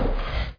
drag5.wav